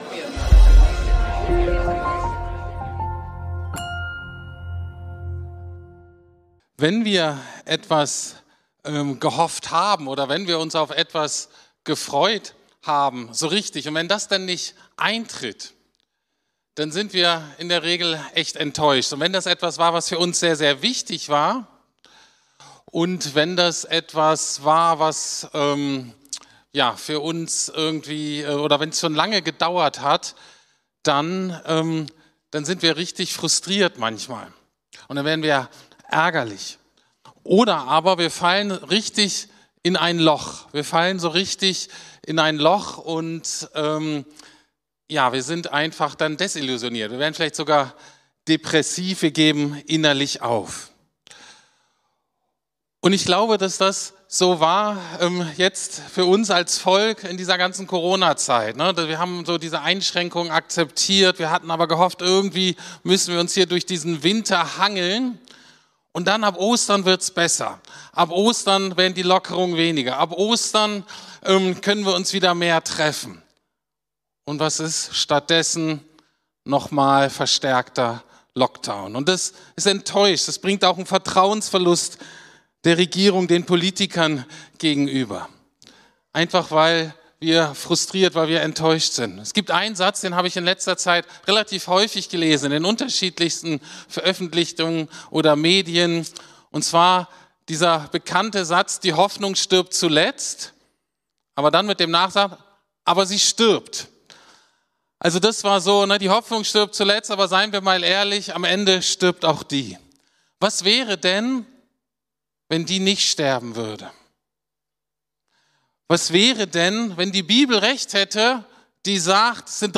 Lebendige Hoffnung ~ Predigten der LUKAS GEMEINDE Podcast